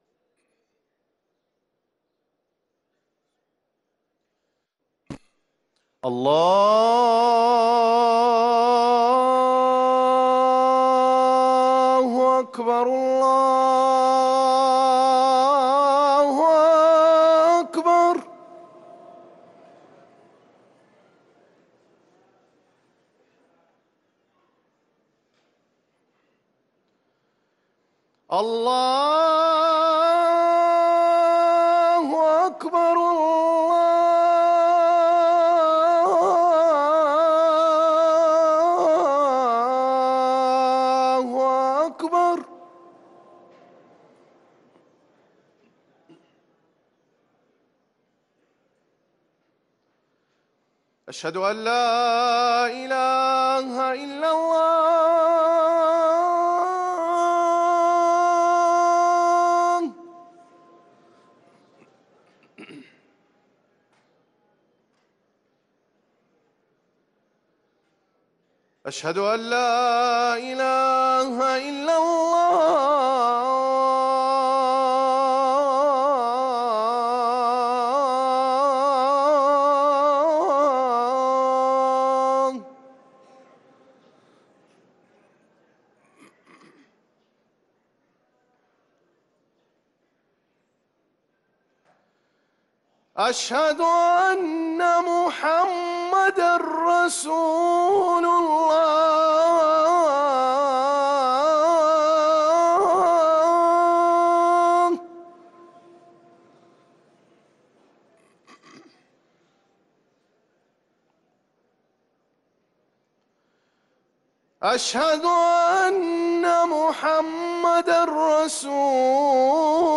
أذان العصر للمؤذن أسامة الأخضر الثلاثاء 13 رمضان 1444هـ > ١٤٤٤ 🕌 > ركن الأذان 🕌 > المزيد - تلاوات الحرمين